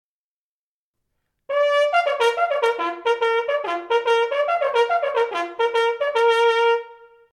This is the rhythm of the bugle call used in the British Army to signal the beginning of the meal.